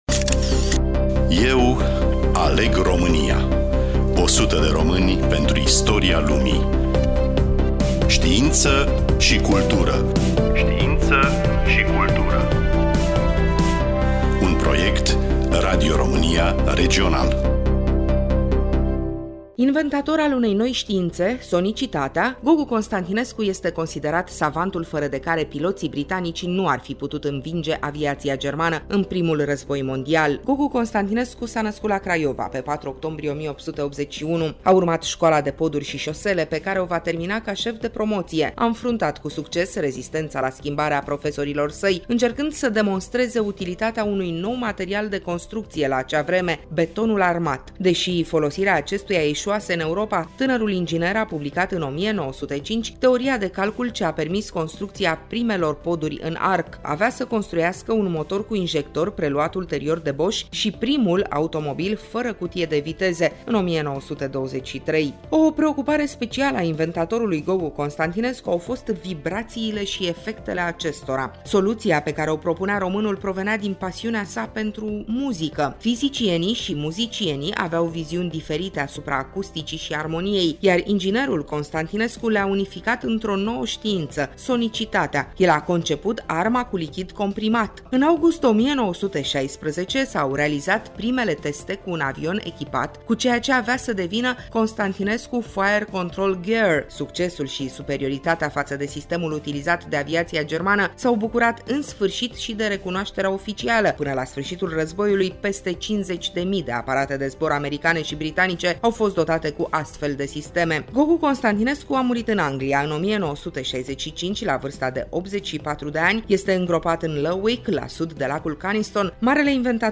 Studioul: Radio Romania Oltenia-Craiova